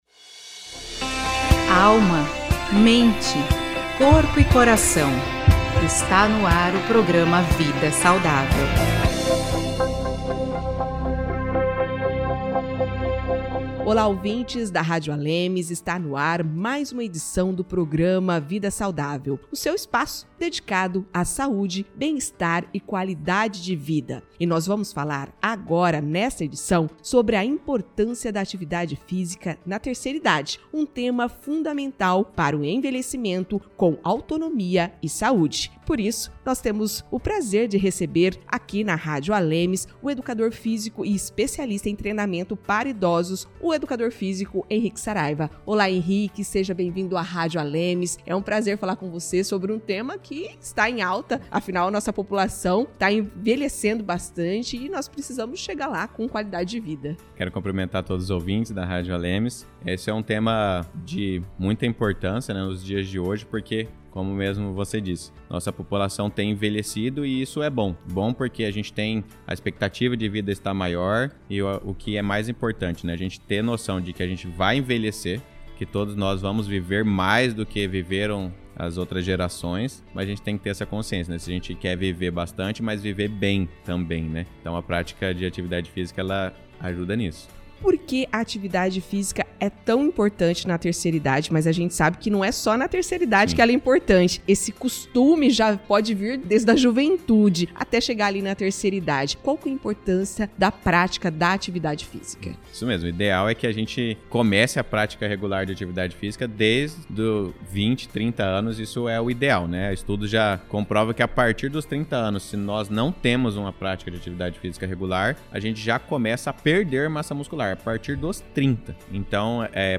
Em entrevista ao programa Vida Saudável